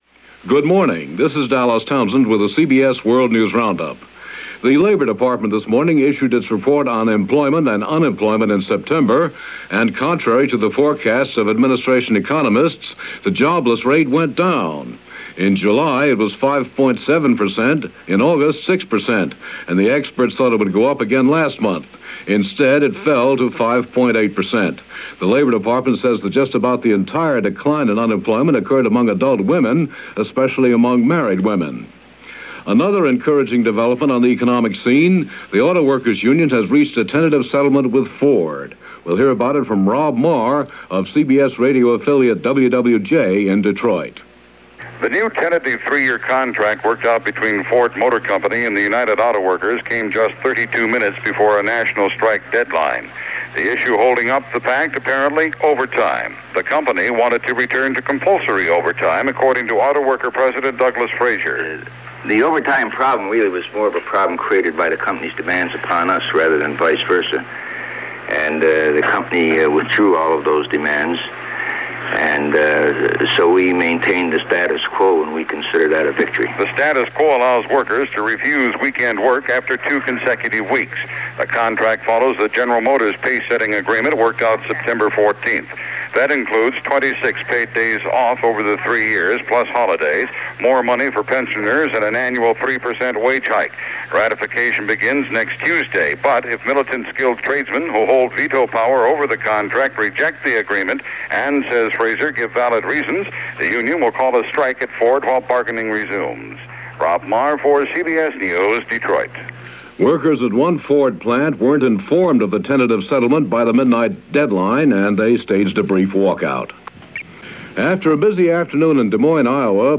News for Oct. 5, 1979
That, and a lot more, was how this day rolled in 1979 via Dallas Townsend and The CBS World News Roundup.